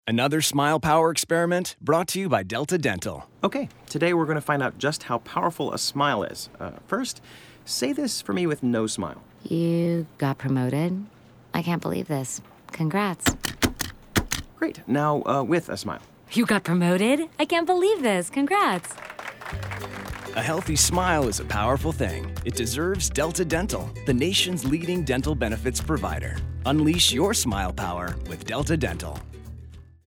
But in this radio extension of the "Smile Power" campaign, we explored the way a smile sounds. You can hear it in their voice.